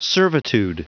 Prononciation du mot servitude en anglais (fichier audio)